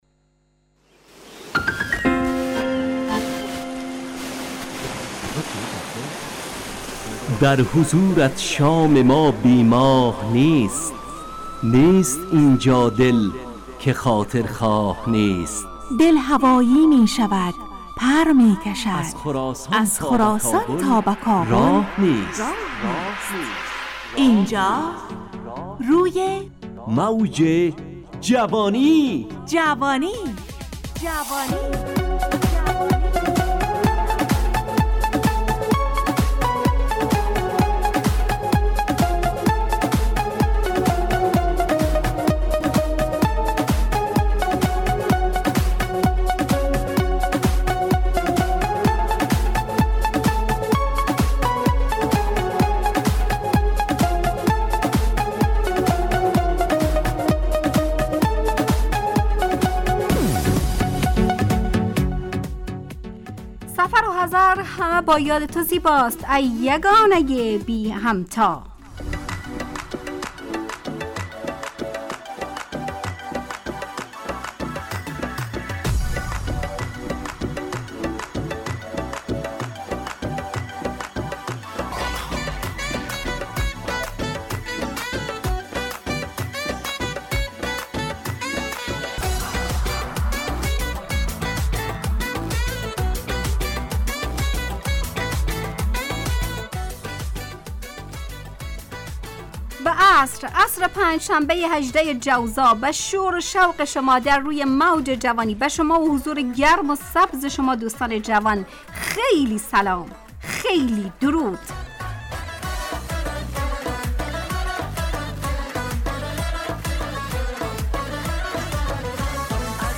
روی موج جوانی، برنامه شادو عصرانه رادیودری.
همراه با ترانه و موسیقی مدت برنامه 70 دقیقه . بحث محوری این هفته (رهبر و راهنما) تهیه کننده